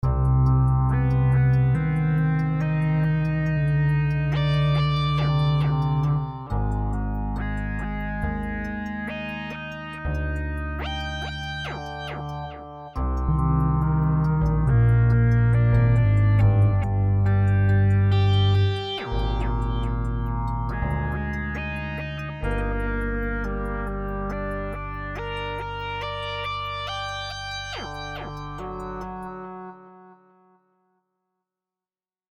Hip-hop Энергичный 120 BPM